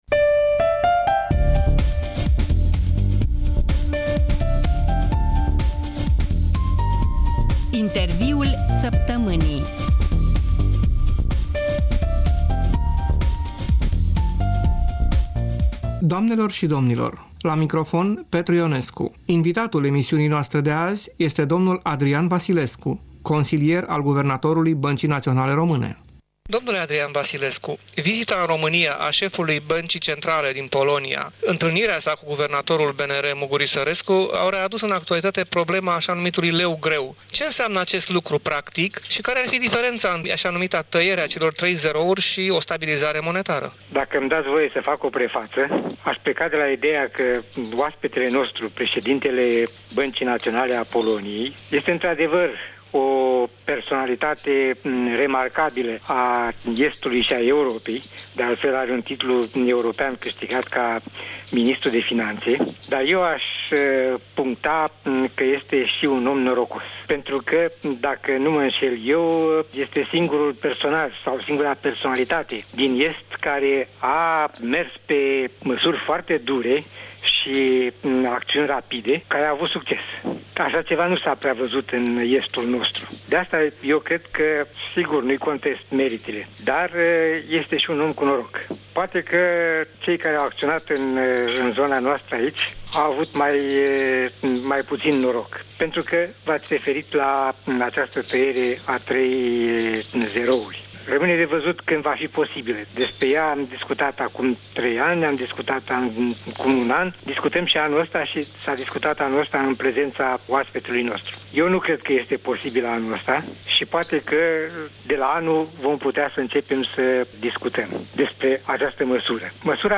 Interviul săptămînii